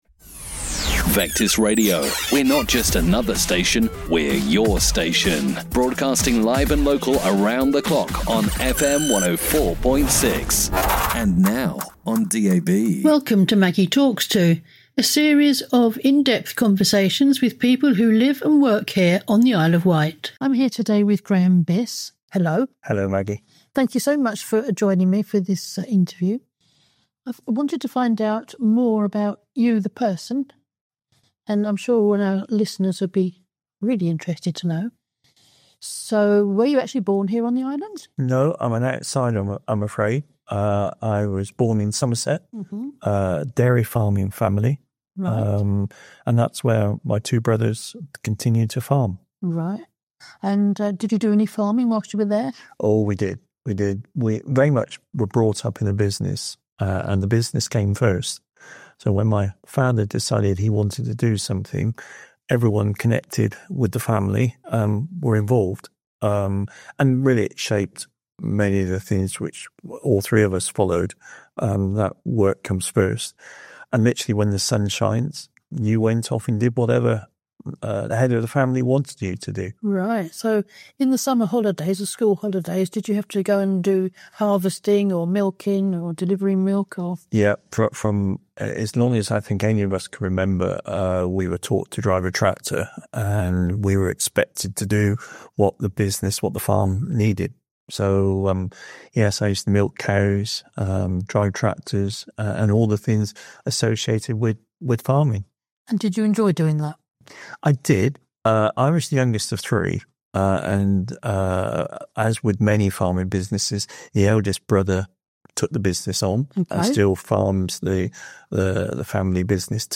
All our interviews from our incredible volunteering team, Catch up with guests that you may have missed on FM 104.6
Vectis Interviews 2026